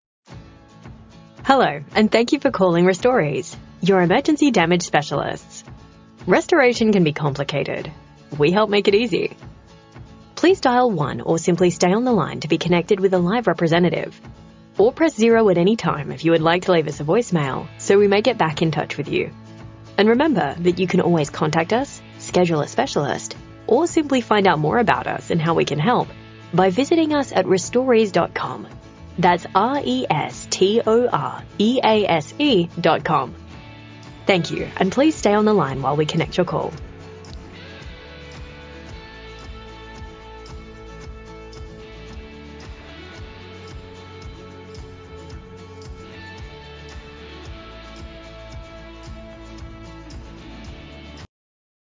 Friendly Professional Greetings for Your VoIP Phone System